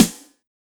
BEAT SD 01.WAV